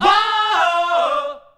WAAOOHOO.wav